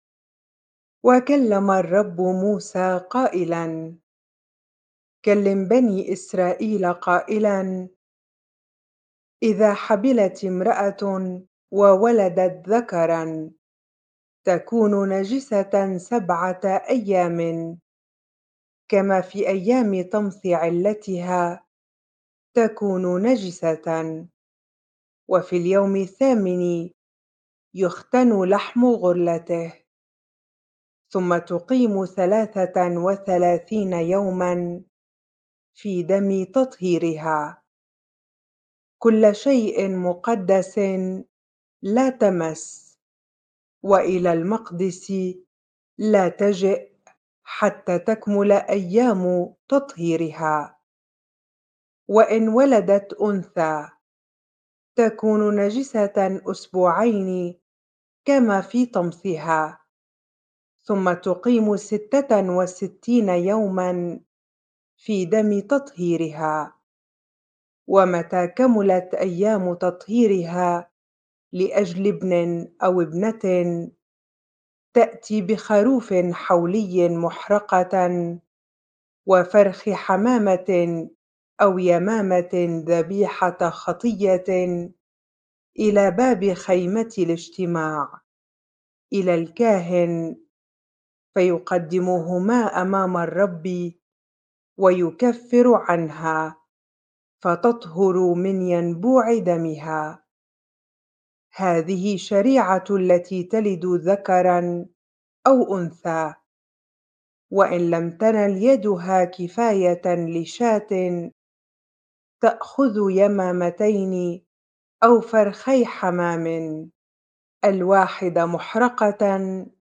bible-reading-leviticus 12 ar